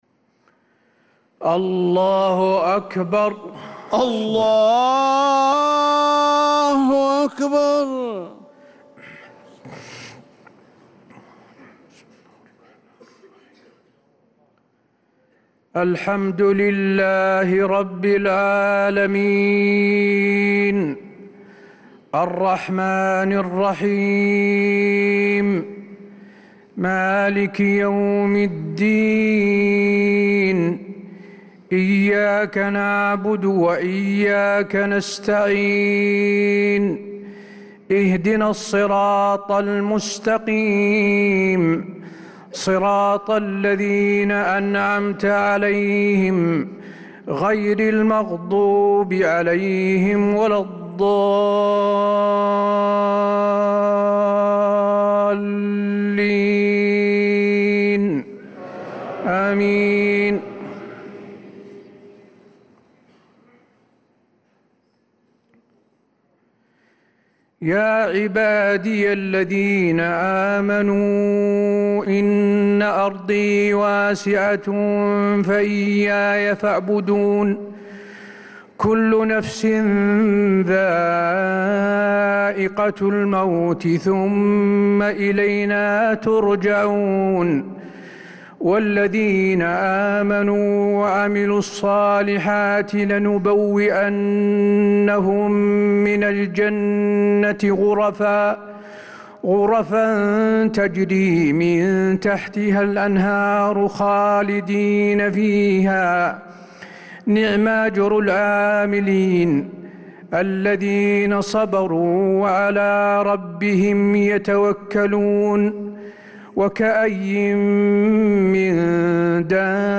صلاة العشاء للقارئ حسين آل الشيخ 14 رجب 1446 هـ